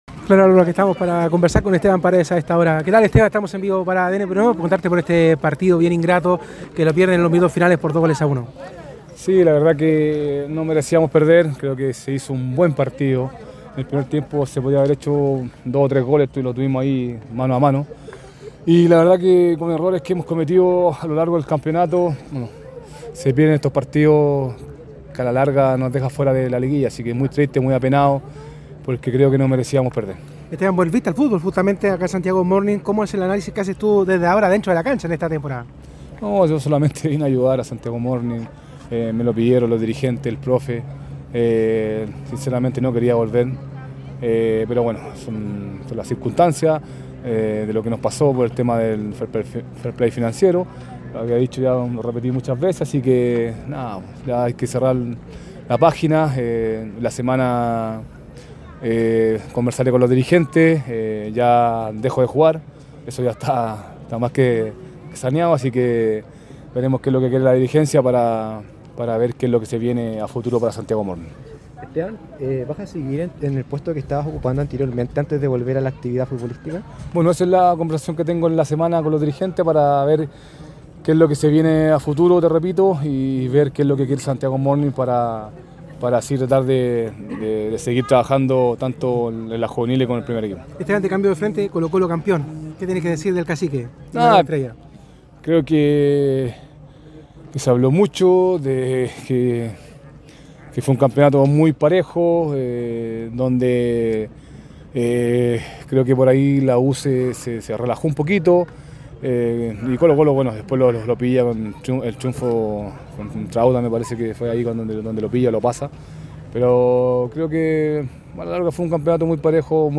Estoy muy triste, muy apenado”, señaló el delantero en diálogo con ADN Deportes.